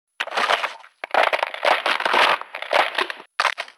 塑料球.mp3